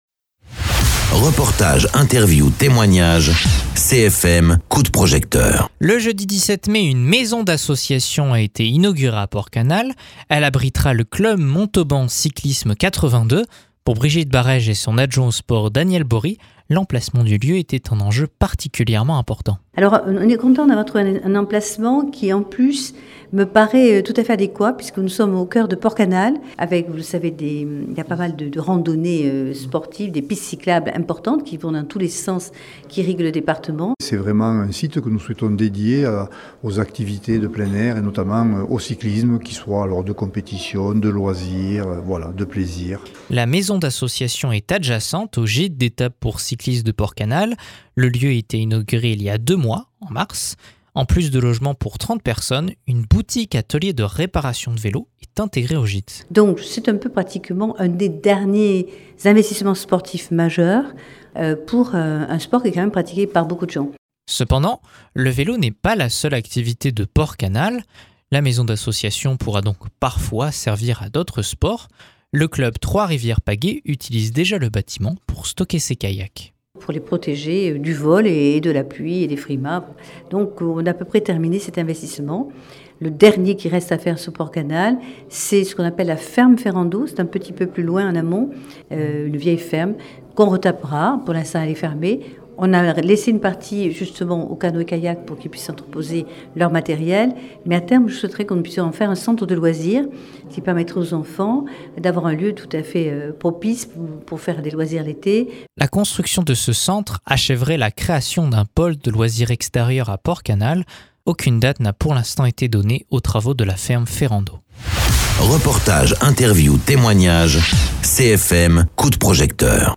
Interviews
Invité(s) : Brigitte Barèges, maire de Montauban et Daniel Bory, adjoint délégué à la Politique sportive de Montauban